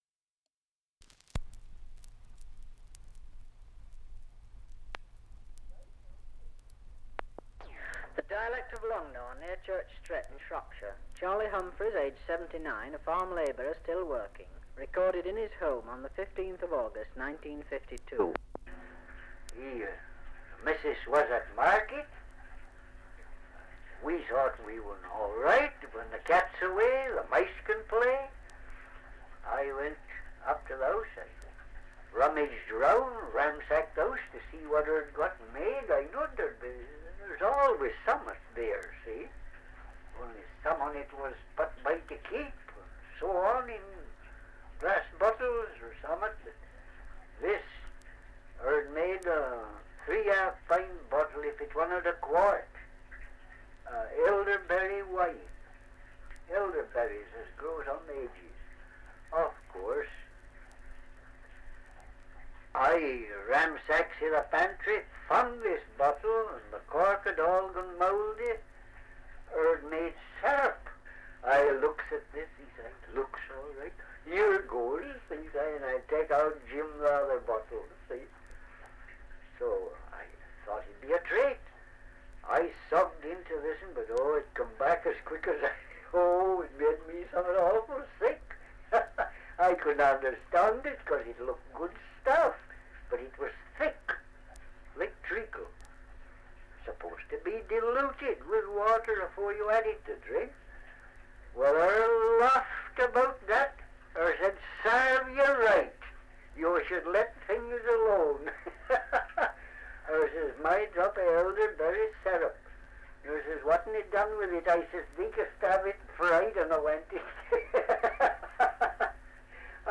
Dialect recording in Longnor, Shropshire
78 r.p.m., cellulose nitrate on aluminium